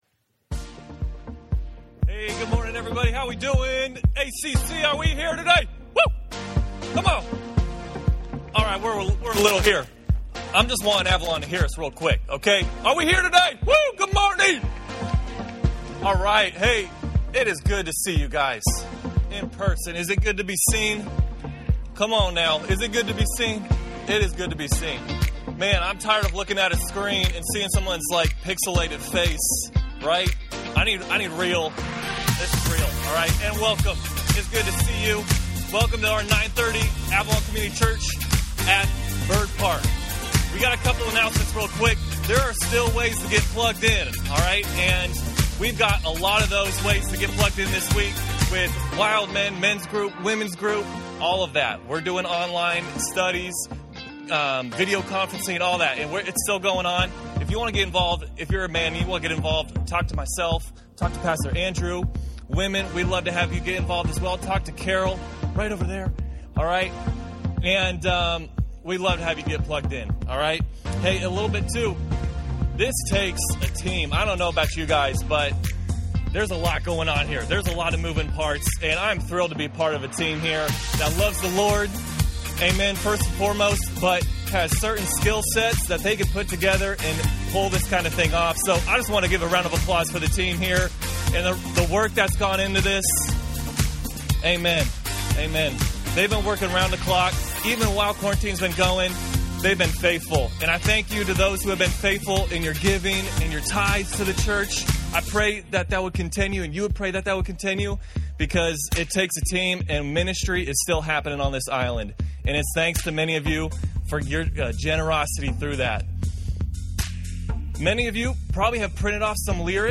Bird Park Service 6/28